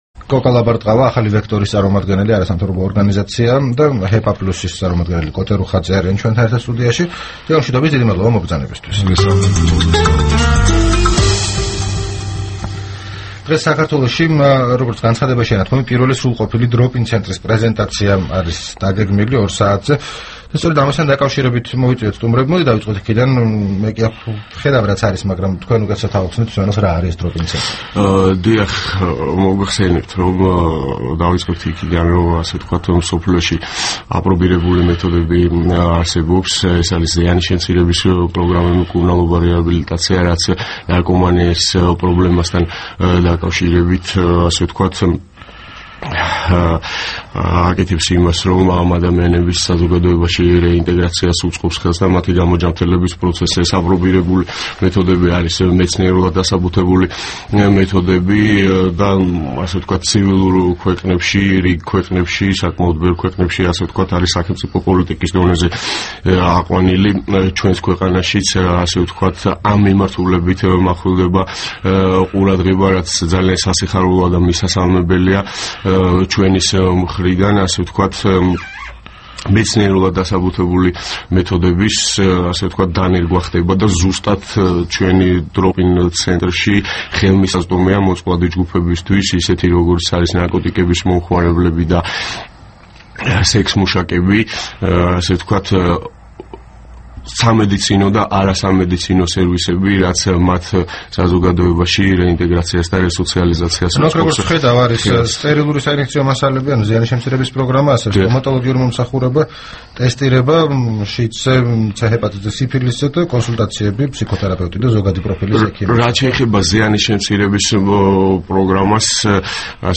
რადიო თავისუფლების თბილისის სტუდიაში
საუბარი